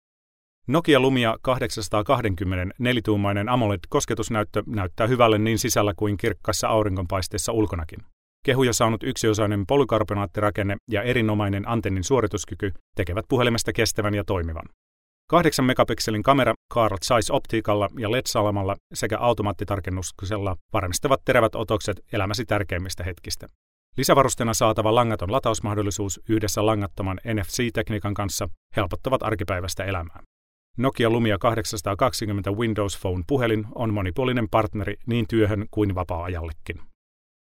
Fince Seslendirme
Erkek Ses